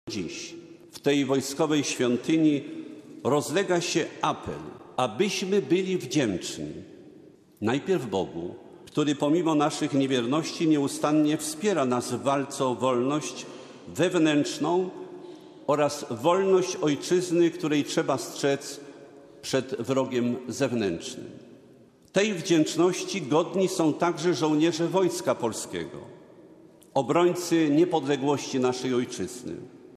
Biskup polowy Wojska Polskiego Józef Guzdek powiedział podczas mszy za ojczyznę w Katedrze Polowej w Warszawie, że potrzebne jest powstanie muzeum Bitwy Warszawskiej, by kolejnym pokoleniom przybliżać prawdę o tym wydarzeniu.
Biskup Guzdek powiedział podczas homilii, że pamięć o bohaterach powinna kształtować przyszłość narodu.